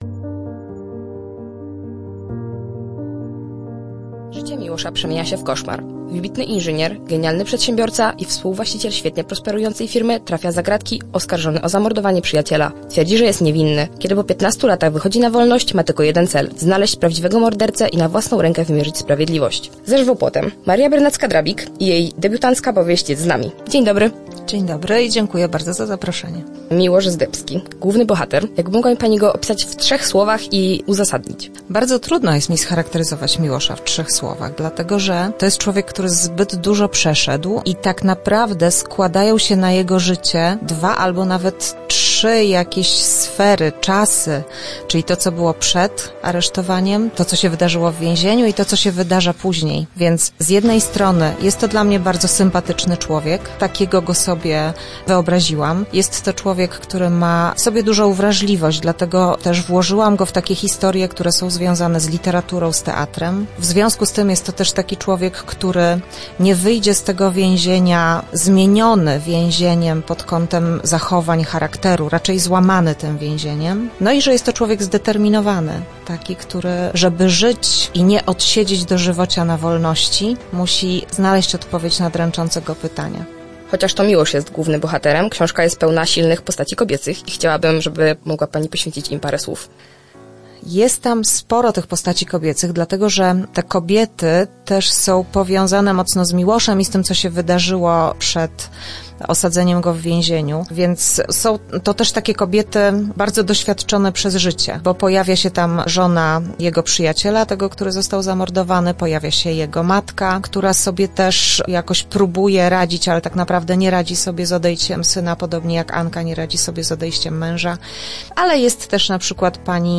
Jak więzienie może wpłynąć na człowieka? W naszym studiu gościliśmy